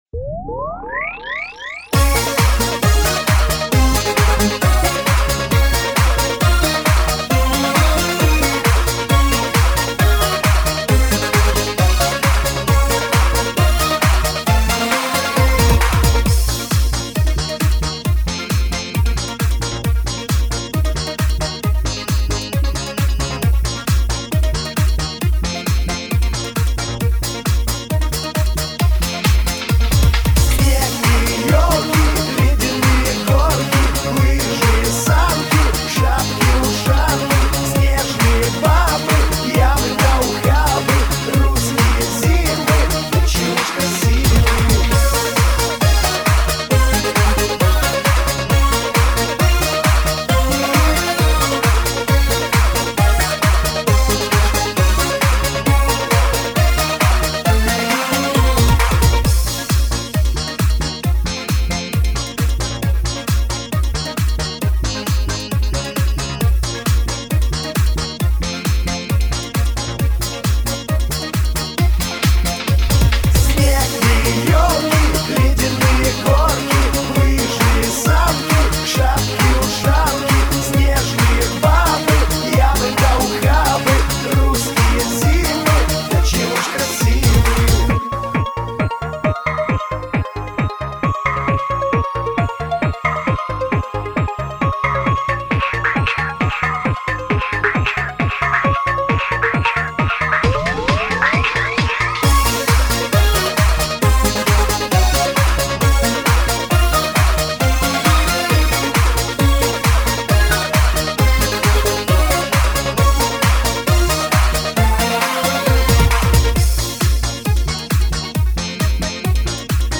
Главная » Песни » Новогодние песни
Слушать или скачать минус
Скачать минус